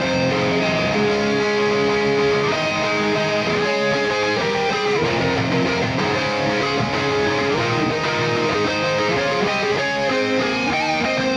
例えばツインギター構成でこんな感じのトラックだったとします。
guitar1が左側担当、guitar2が右側担当です。